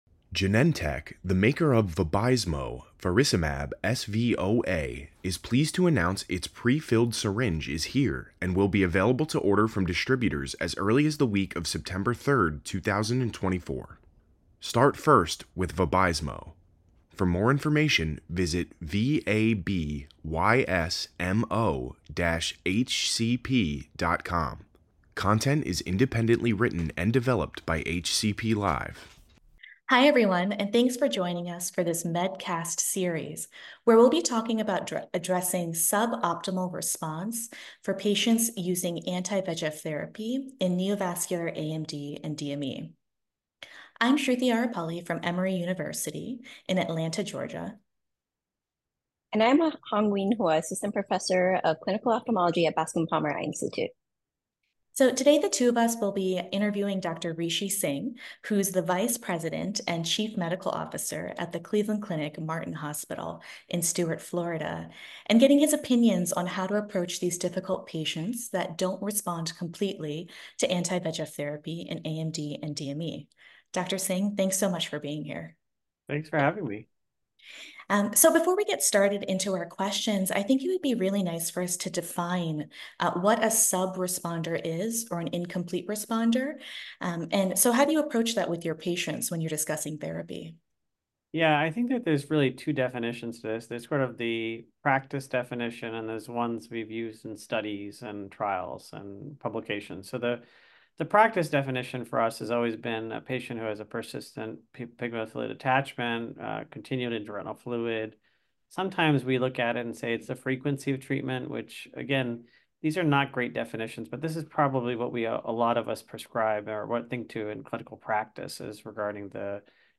A panel of ophthalmologists discuss strategies for managing AMD and DME patients who exhibit suboptimal responses to their current anti-VEGF therapy.